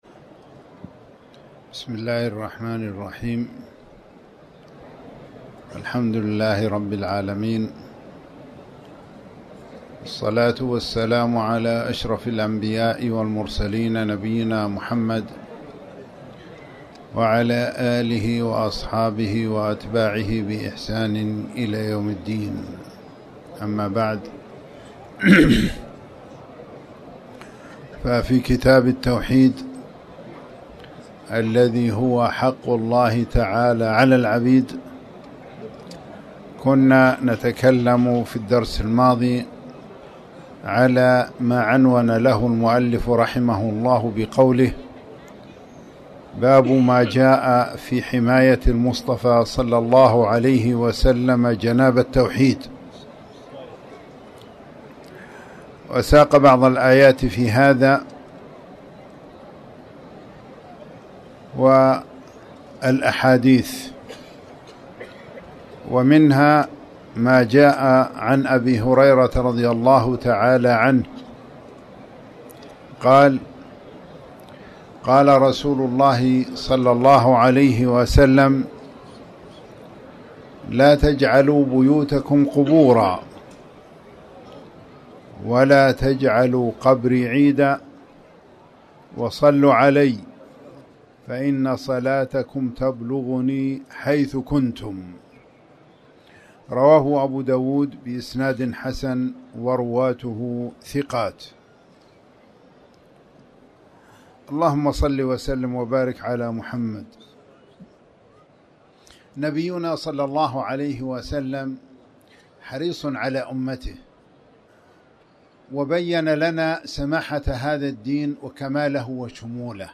تاريخ النشر ١٠ ربيع الأول ١٤٤٠ هـ المكان: المسجد الحرام الشيخ